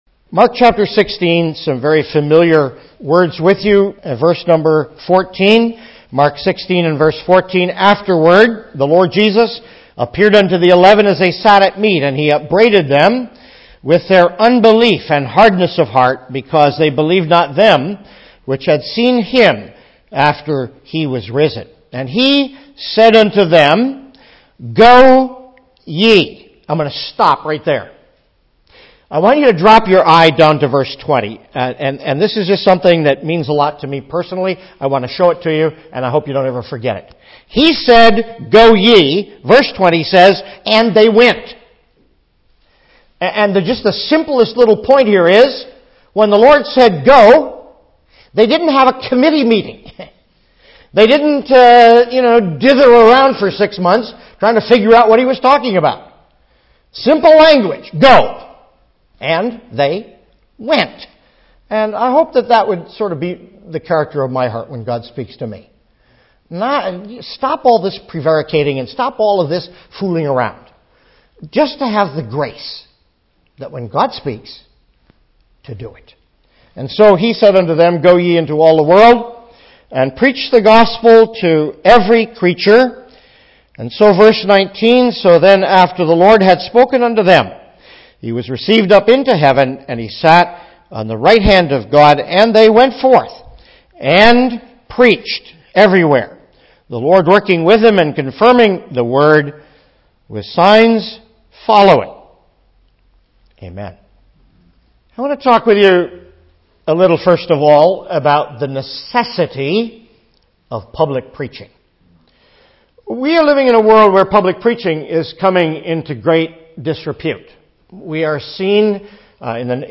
2016 Easter Conference